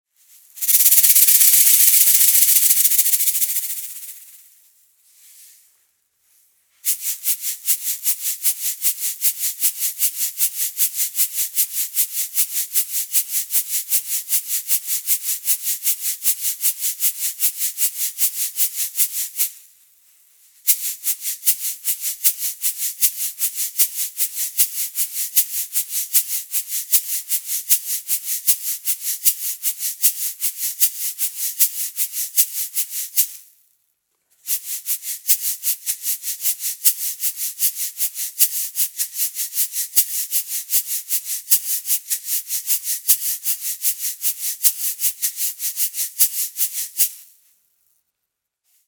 Size: MediumLength: 17 cmColor: RedMaterial: PlasticFeature 1: Two individual shakers connected as one instrumentFeature 2: Perfect for percussionist…